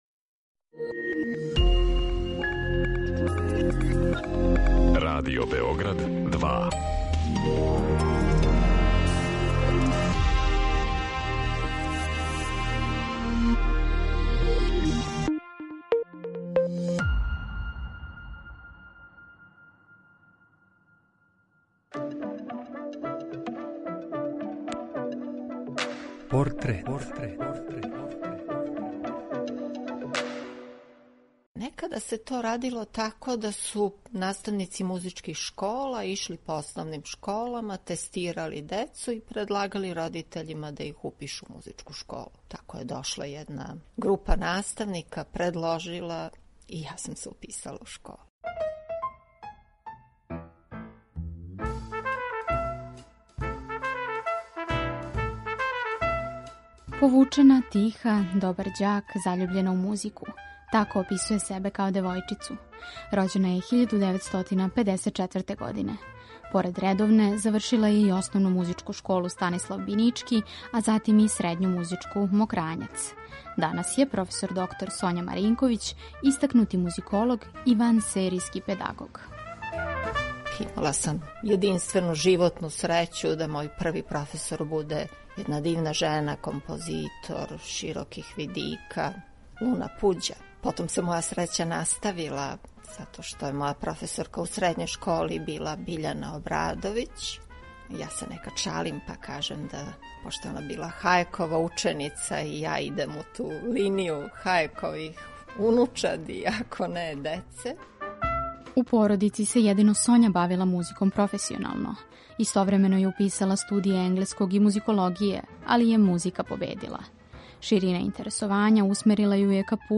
Приче о ствараоцима, њиховим животима и делима испричане у новом креативном концепту, суптилним радиофонским ткањем сачињеним од: интервјуа, изјава, анкета и документраног материјала. О портретисаним личностима говоре њихови пријатељи, најближи сарадници, истомишљеници…